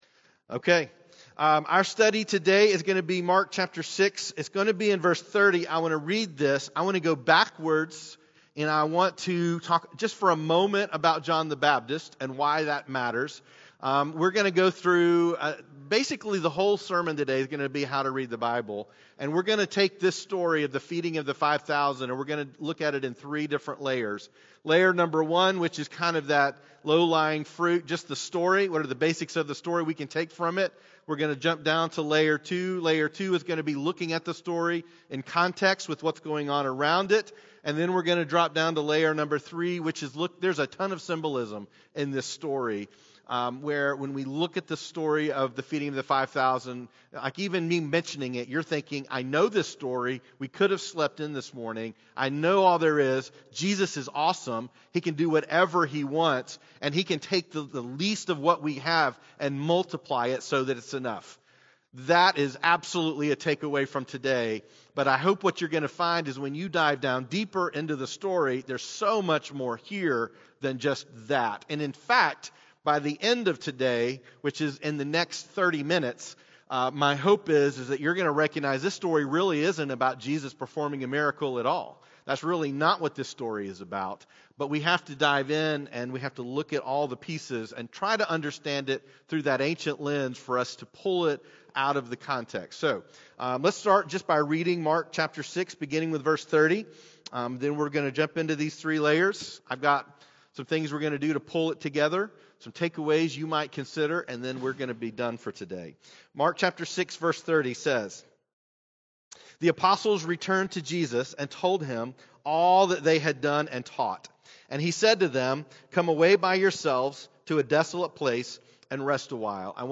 Weekly teaching of Journey Church: A faith community in Chattanooga devoted to making Jesus famous and healing the brokenhearted.